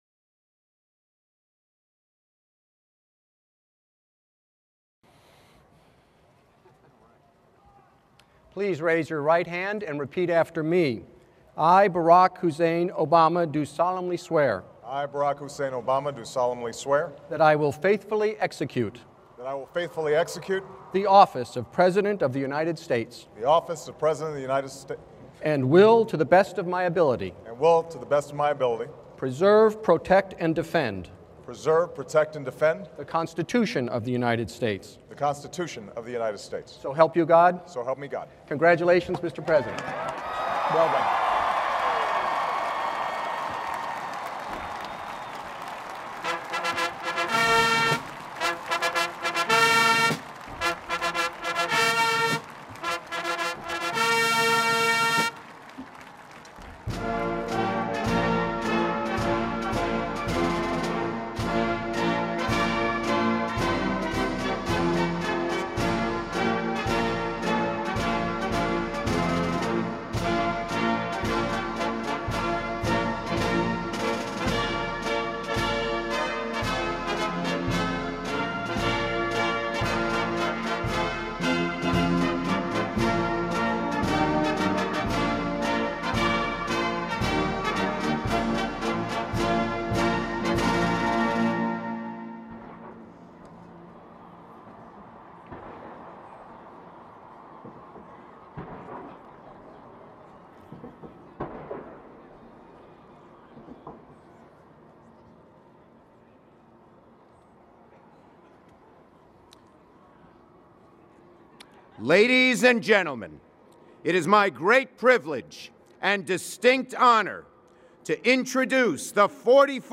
On January 21st, 2013, President Barack Obama took the oath of office as the 44th president of the United States and delivered his second inaugural.
BarackObamaSecondInauguralAddress.mp3